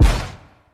T-Minus Stomp.wav